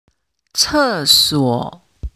四声の単語
「廁所 cè suǒ」の発音